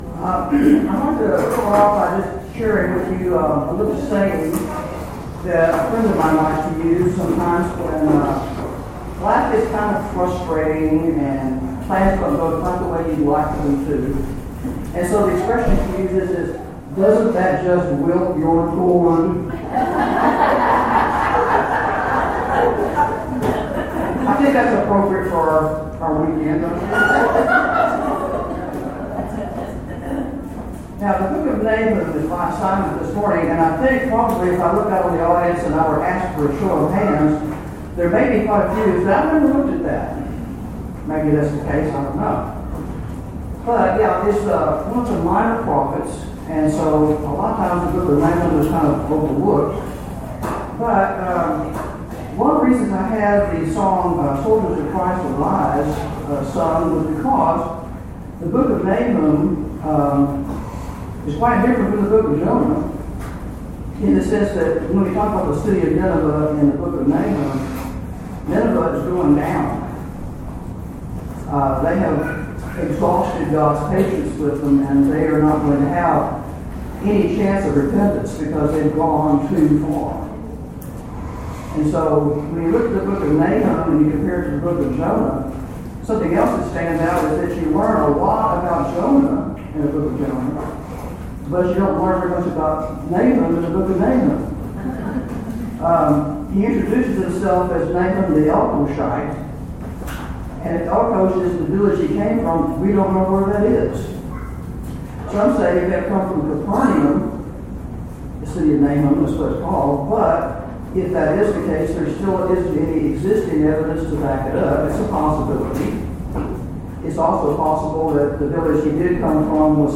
Event: 12th Annual Texas Ladies in Christ Retreat Theme/Title: Studies in Jonah and Nahum
Ladies Sessions